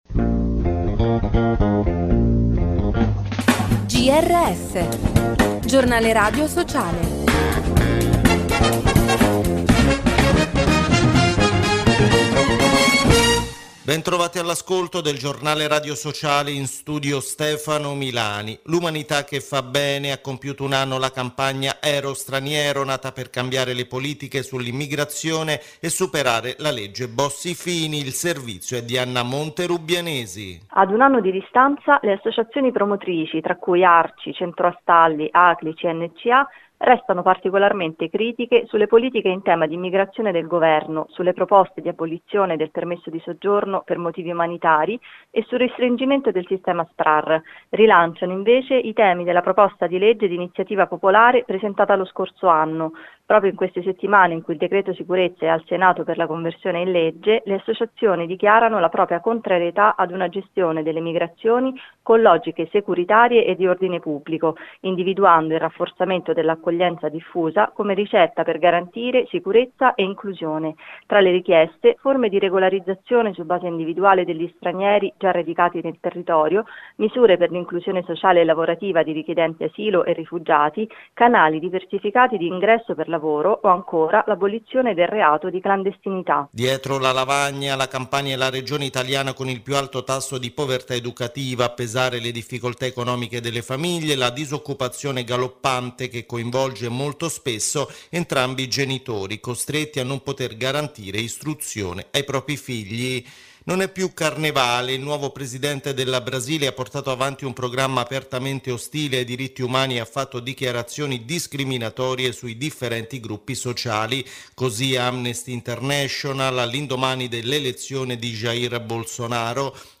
GRS WEEK – Verso il 2018: il futuro è dei diritti?